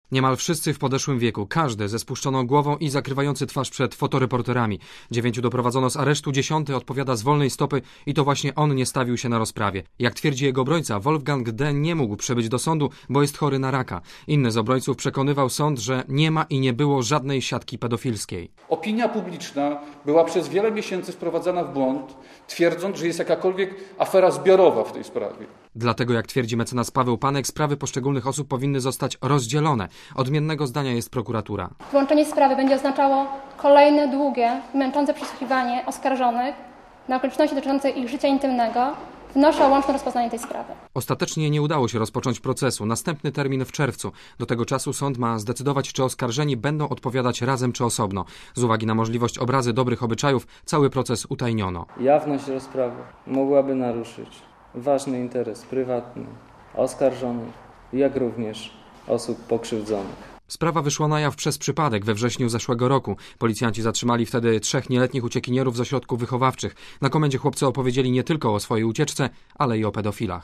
Posłuchaj relacji reportera Radia Zet, który był na sali sądowej (550 KB)